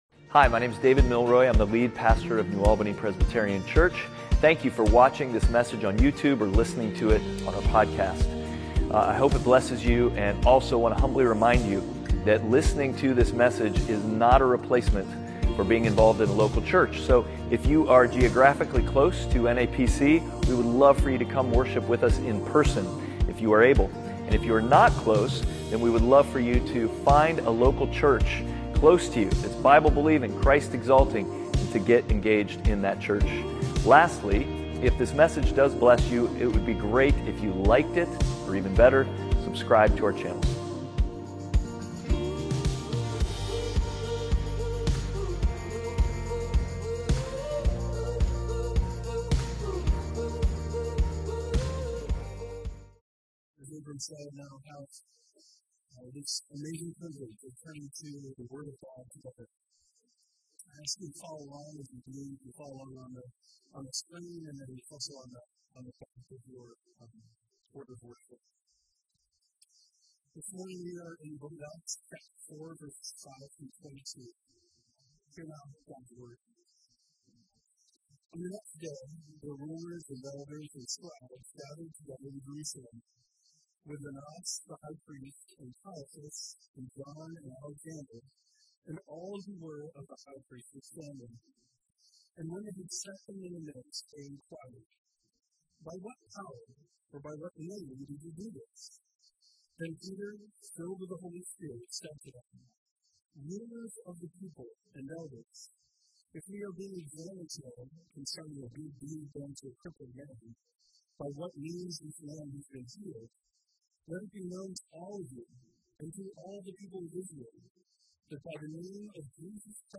Passage: Acts 4:5-22 Service Type: Sunday Worship « Outward